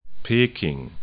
'pe:kɪŋ